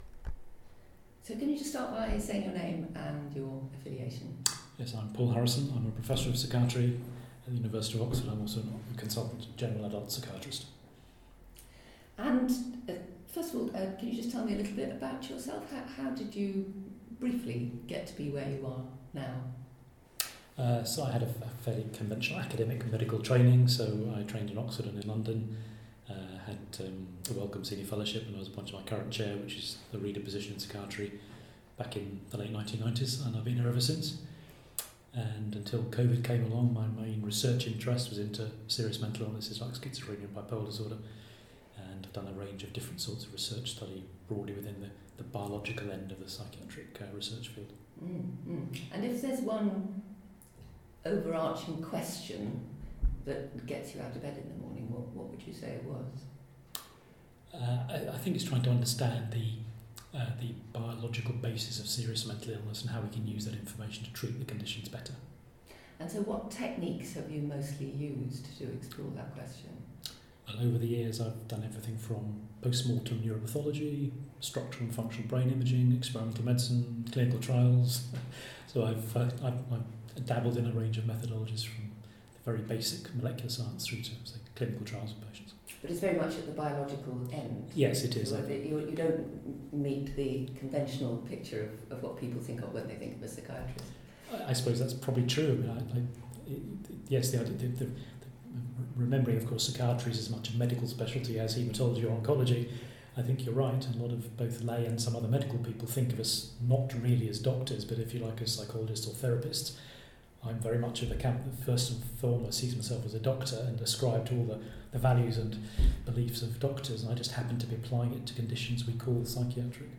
Collecting COVID: Oral Histories